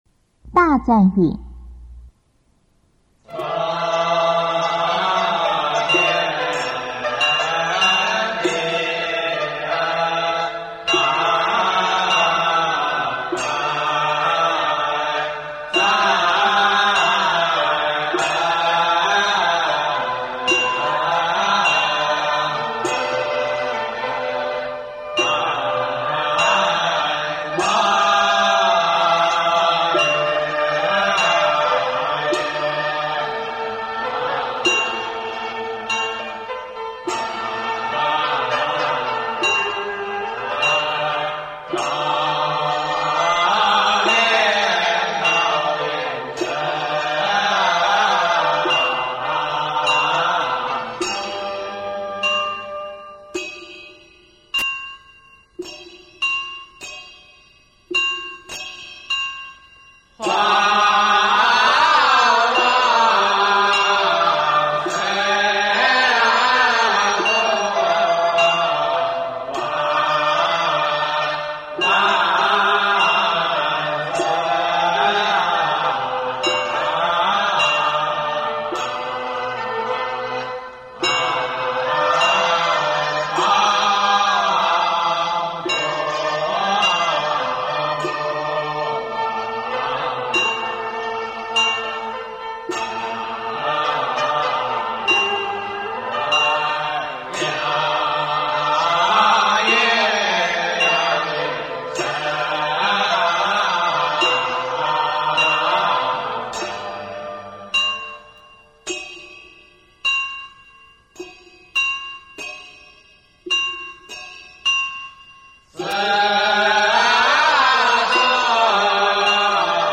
中国道教音乐 全真正韵 大赞韵
简介：道场通用，在道场科仪毕时，就使用这首较长的赞语，对不同的仙圣使用不同的辞语，表示通经功德及忏悔。